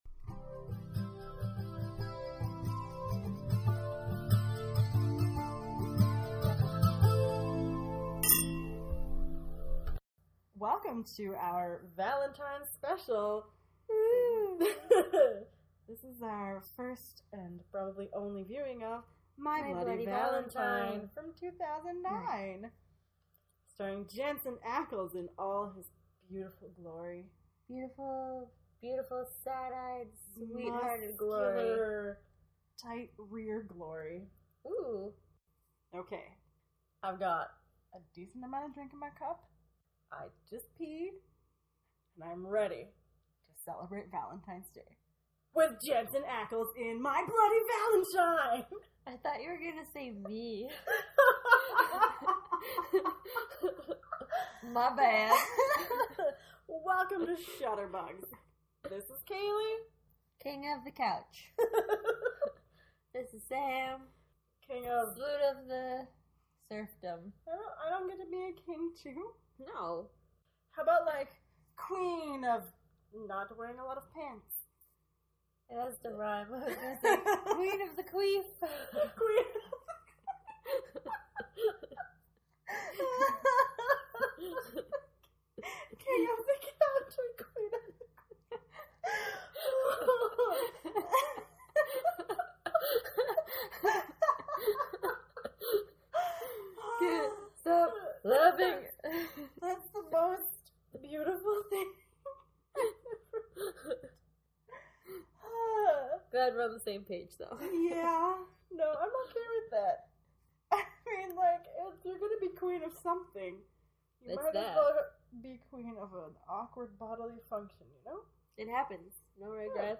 For such a romantic occasion, we watch My Bloody Valentine from 2009, starring the increasingly more attractive Jensen Ackles. So curl up, grab a bottle of wine, and listen as we get drunk and rowdy and enjoy this classic Valentine’s movie.